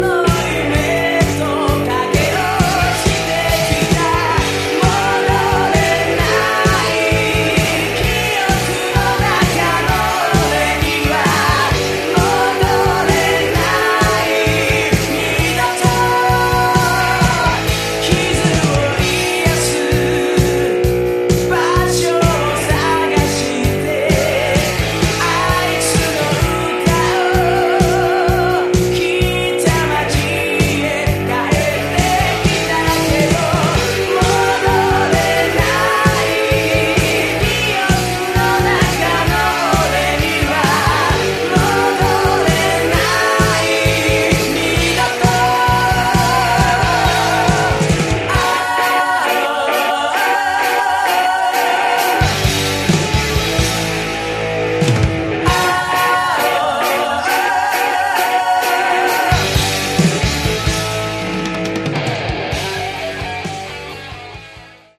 Category: AOR
vocals
guitar
bass
drums
synthesizers